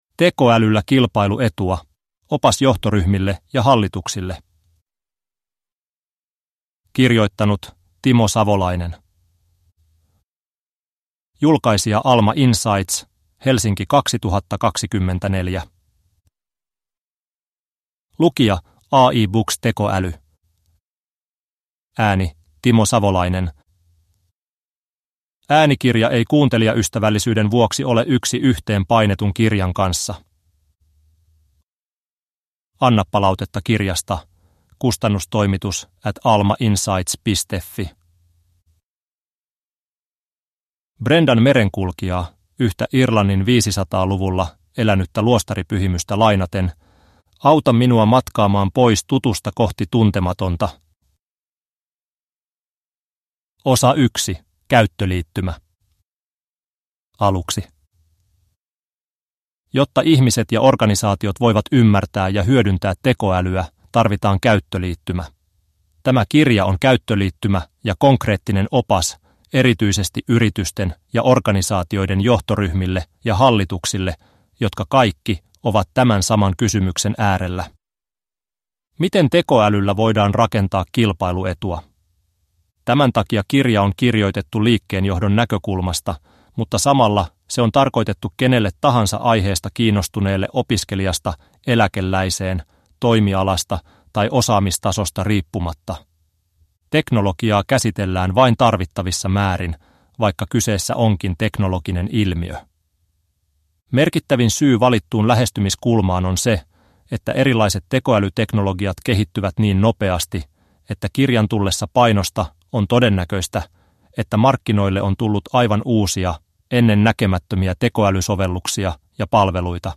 Tekoälyllä kilpailuetua – Ljudbok
Uppläsare: AI (Artificial Intelligence)